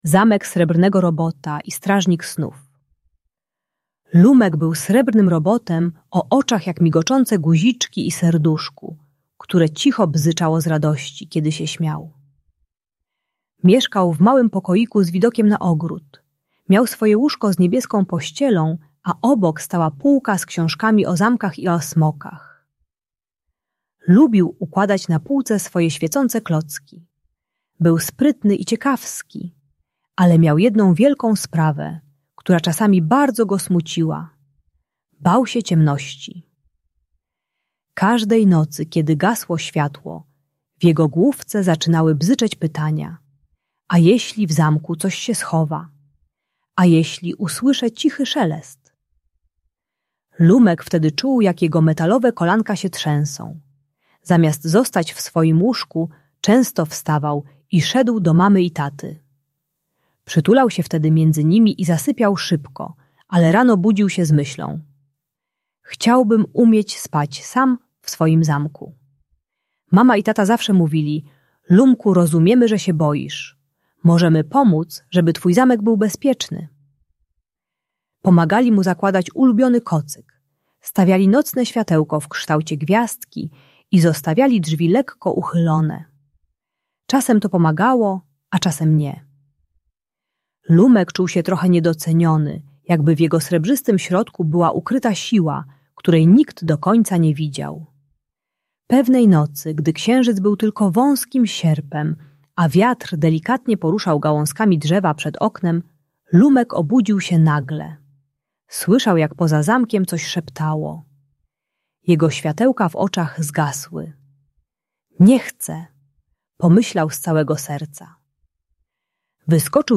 Zamek Srebrnego Robota - Usypianie | Audiobajka